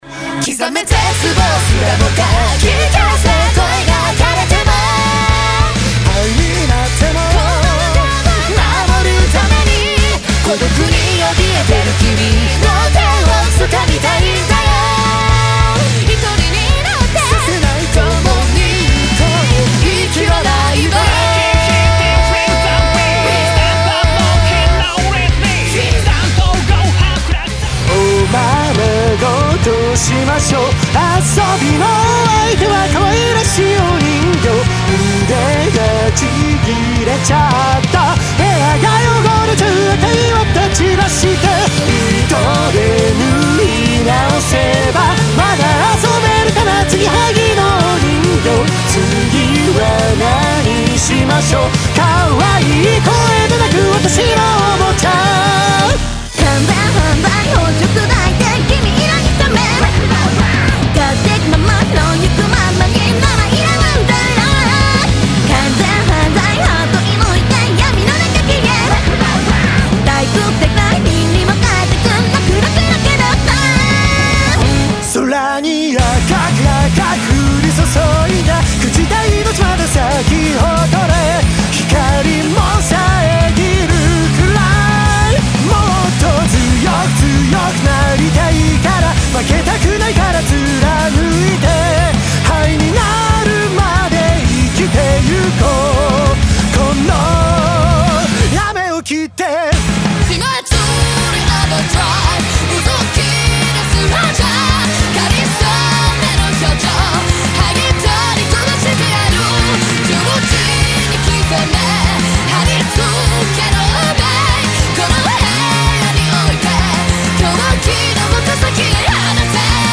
ギターソロ&ラップ
バッキングギター